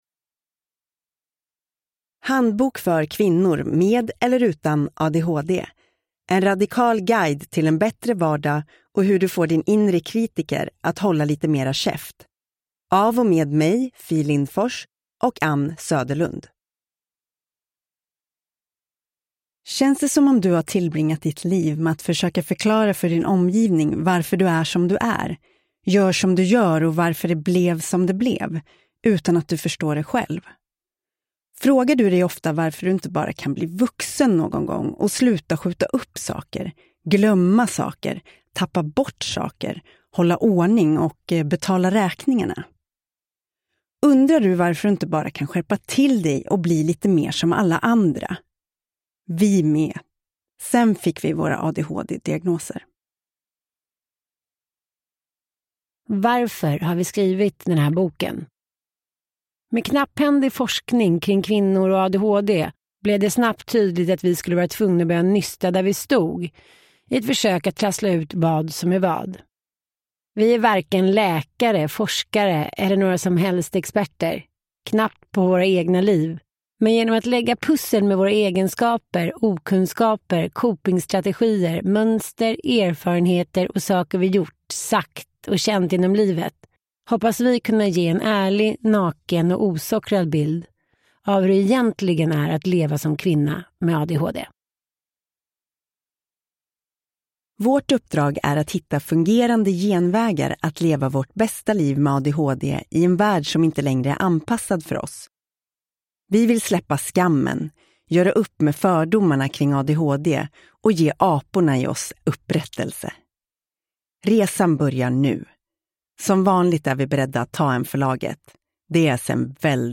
Handbok för kvinnor med (eller utan) ADHD : en radikal guide till en bättre vardag och hur du får din inre kritiker att hålla lite mera käft – Ljudbok